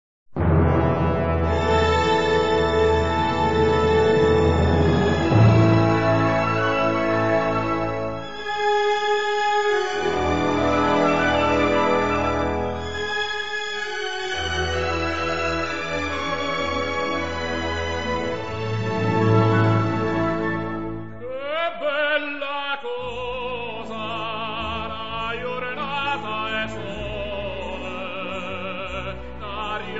• arie
• musica classica
• Classical music